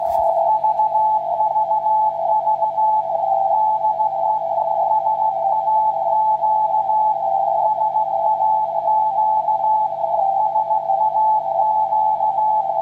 - Ferritantenne mit FET-Vorverstärker und VLF-Konverter auf einen KW-TRX.